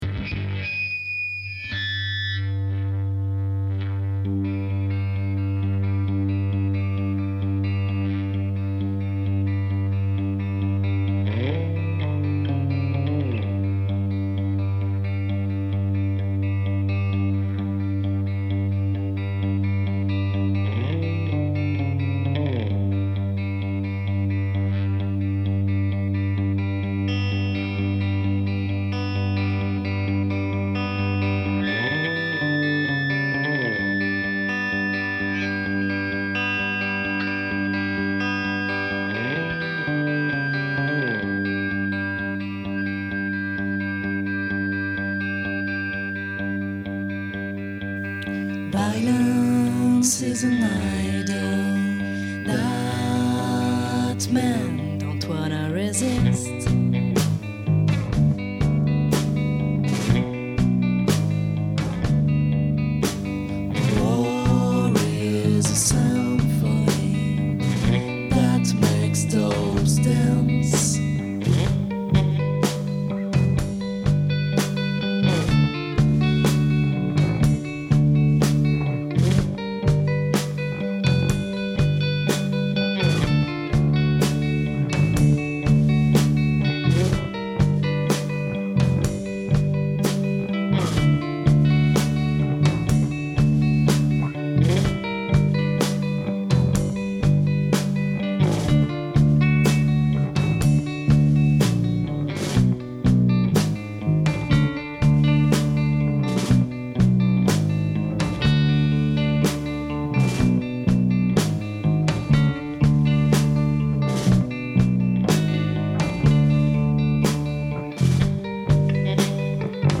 Une autre d'it's all good in the wood maquettée le week end dernier :
Faut écouter fort sinon on entend pas trop le début.